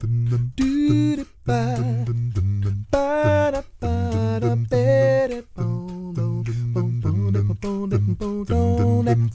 ACCAPELLA 3.wav